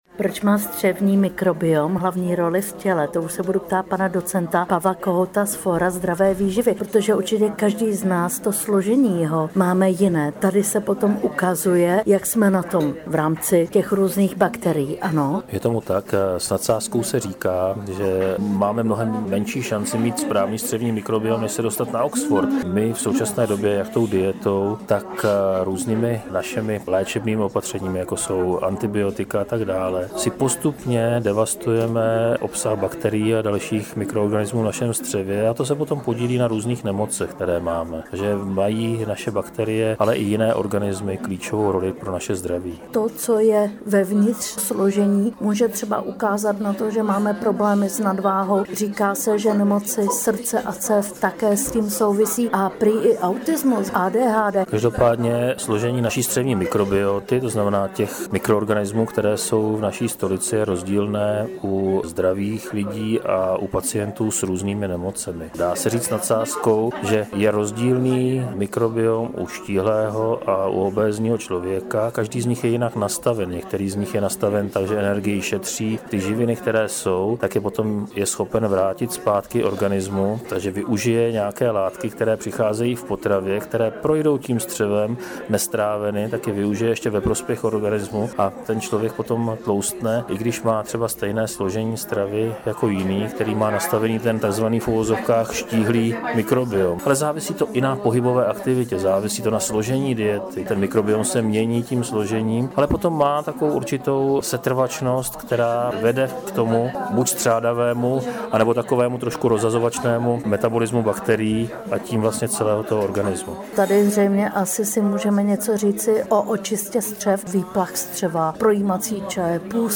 Rozhovor
O důležitost střevního mikrobiomu a novém směru v oblasti si s námi povídal dietinternista a gastroenterolog